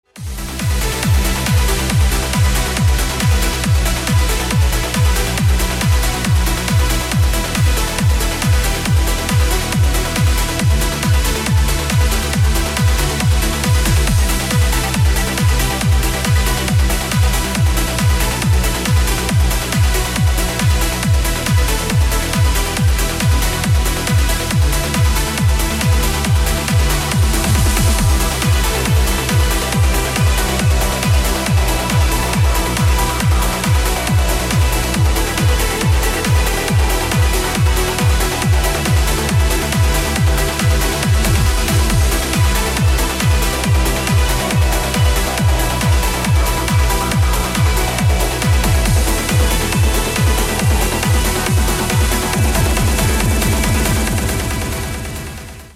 • Качество: 320, Stereo
EDM
электронная музыка
без слов
энергичные
Trance
быстрые